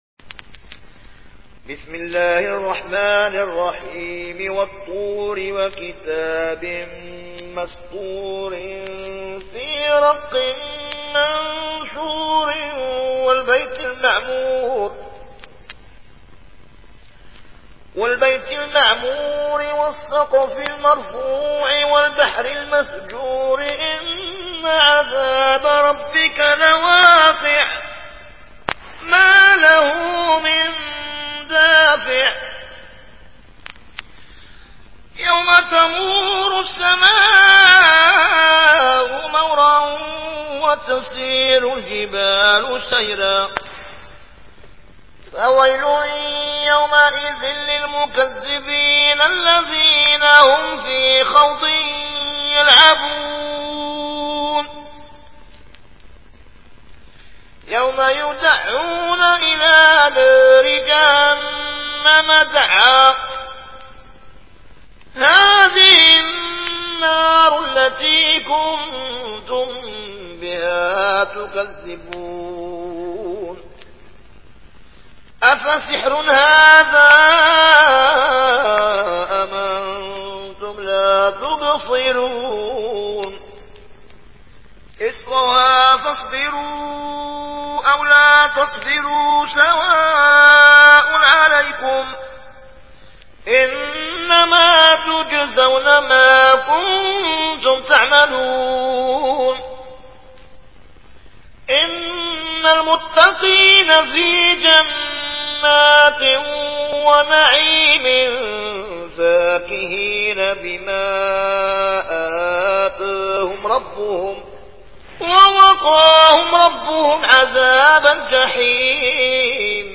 ورش عن نافع